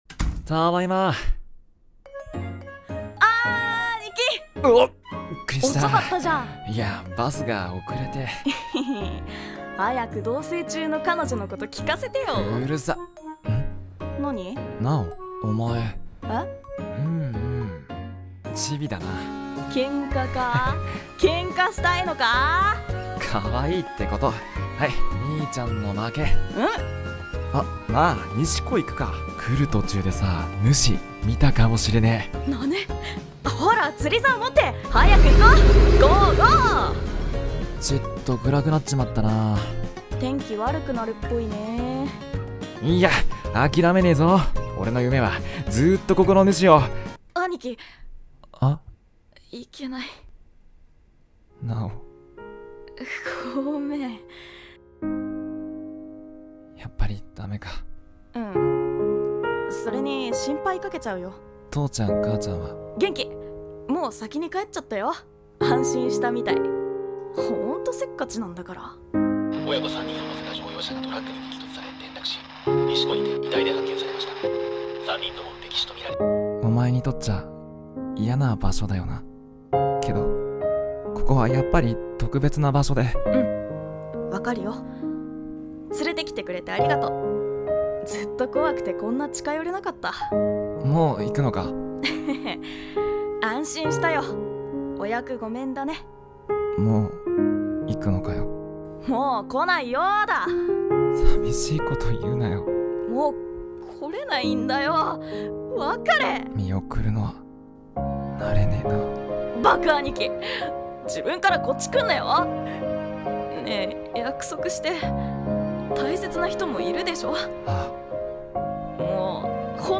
【掛け合い 2人声劇台本】